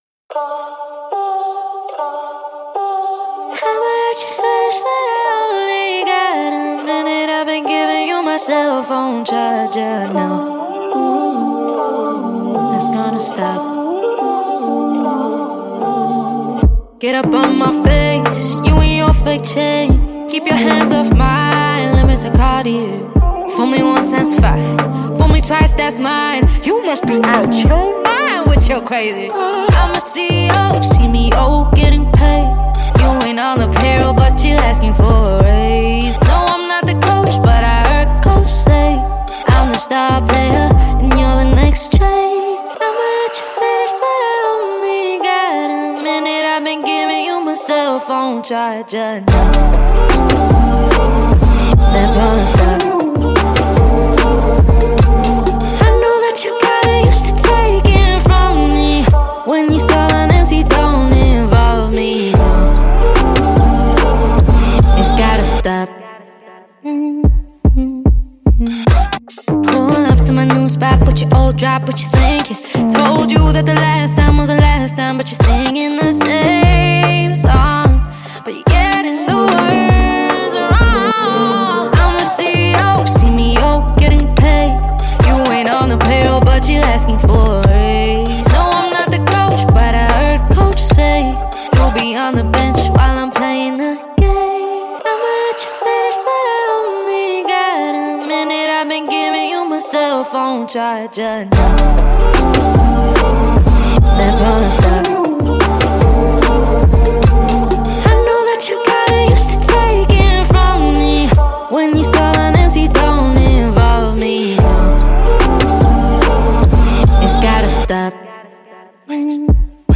South African hip-hop artist